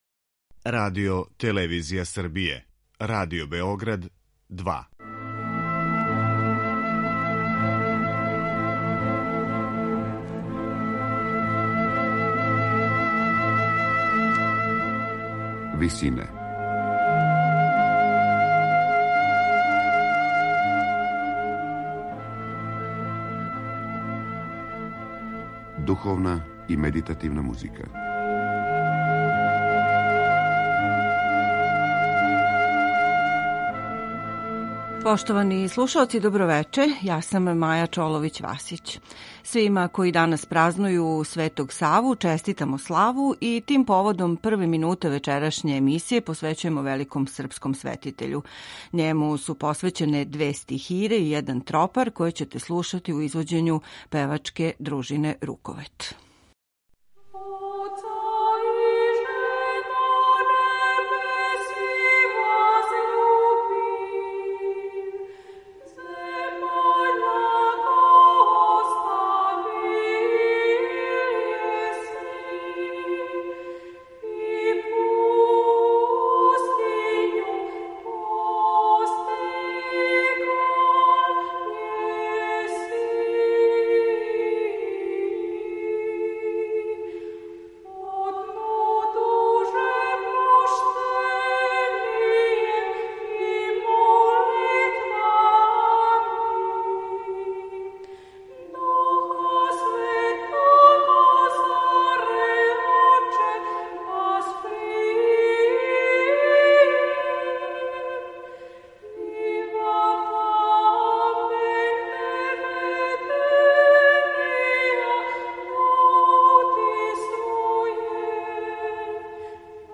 Дело засновано на напевима традиционалног карловачког појања, једноставних хармонија и хорског слога, које има историјски значај у развоју наше музике, први је пут штампанo у Бечу 1862, док је у Србији премијерно изведено тек 1993. године.
мешовити хор